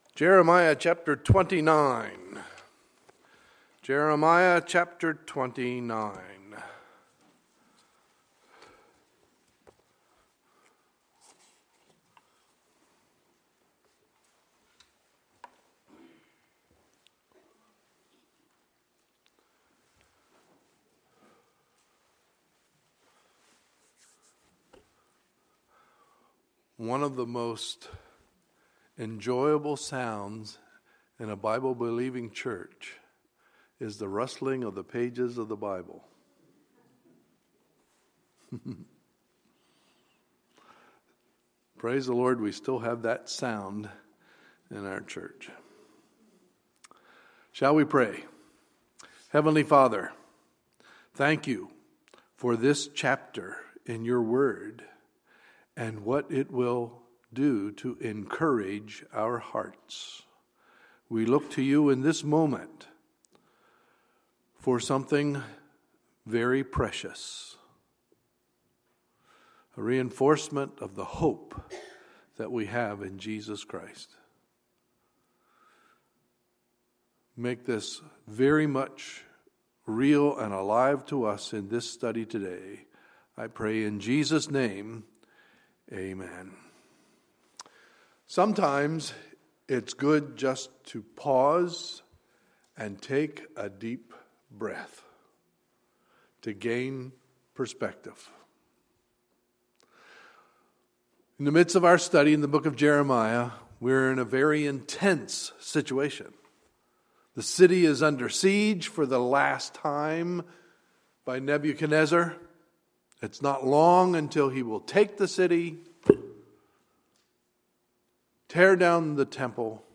Sunday, November 1, 2015 – Sunday Morning Service